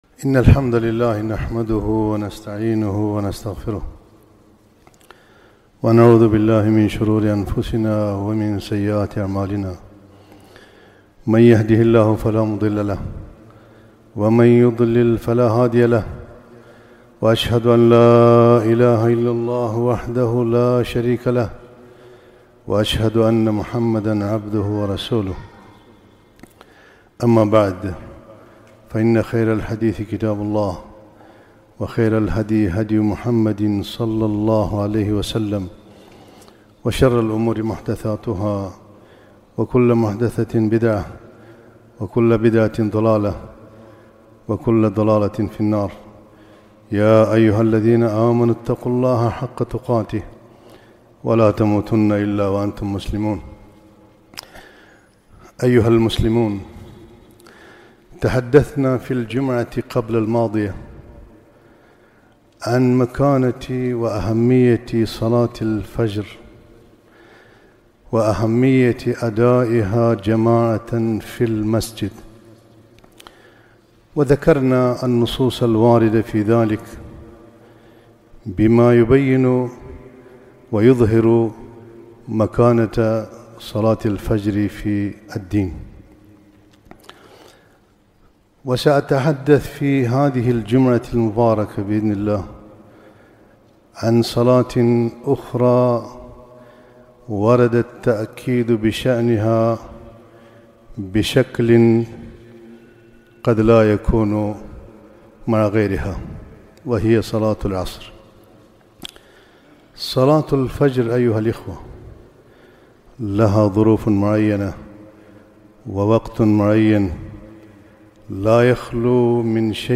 خطبة - صلاة العصر